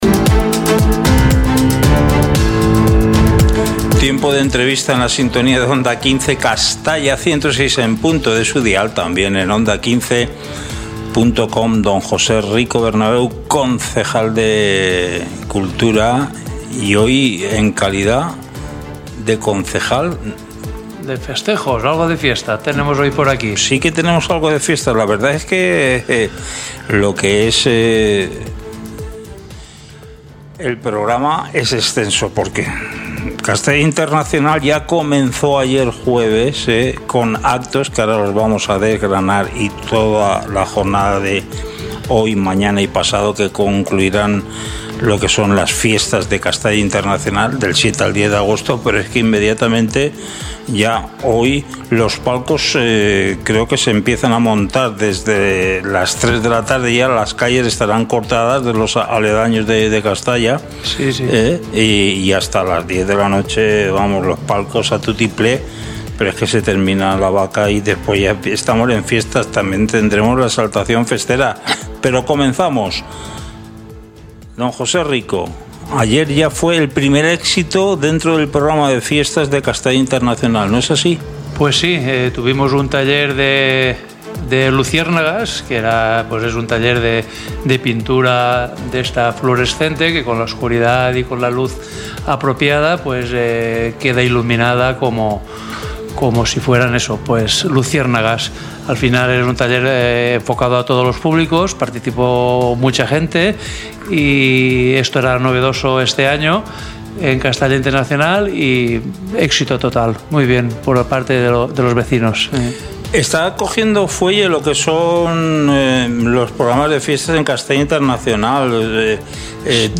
Entrevista a José Rico Bernabeu, Concejal de Festejos del MI Ayuntamiento de Castalla - Onda 15 Castalla 106.0 FM
Hoy en nuestro informativo hablamos con José Rico Bernabeu, Concejal de la Festejos del MI Ayuntamiento de Castalla.